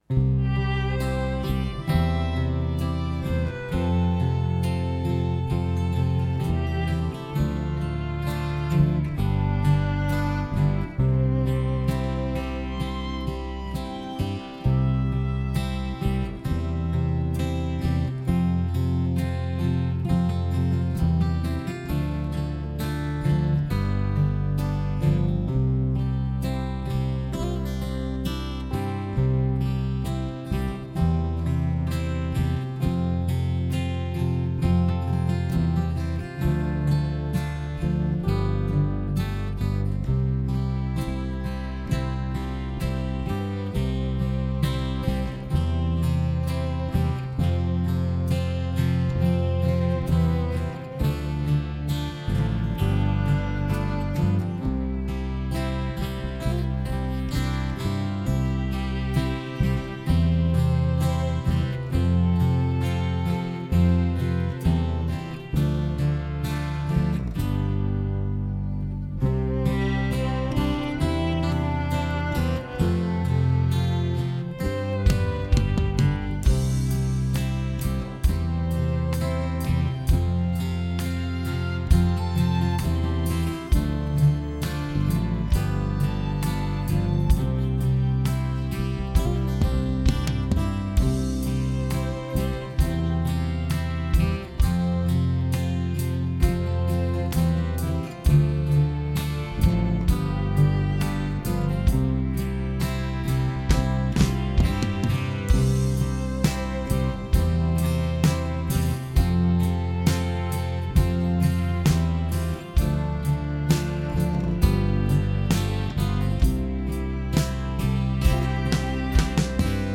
Key of C - Track Only - No Vocal